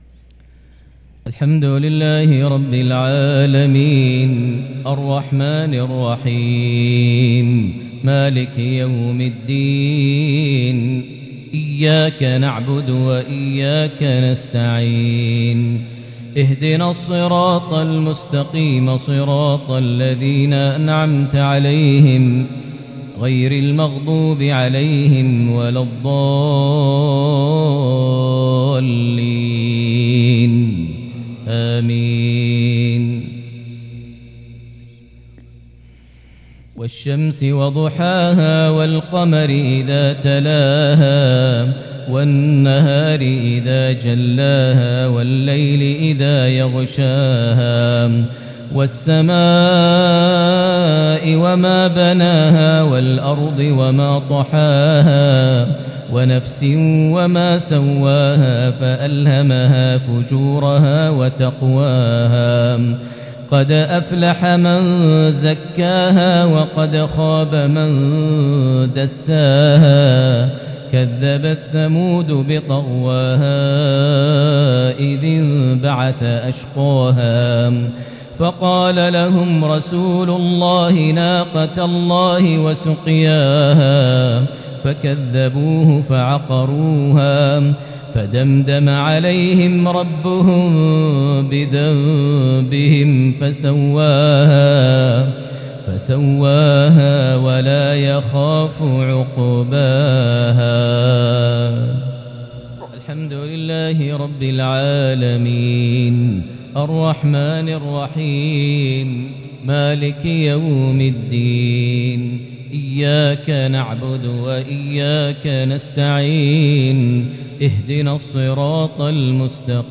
صلاة الجمعة تلاوة لسورتي الشمس - الليل | 29 شعبان 1443هـ| salah_jumua_prayer from 1-4-2022 Surah Ash-Shams + Surah Al-Lail > 1443 🕋 > الفروض - تلاوات الحرمين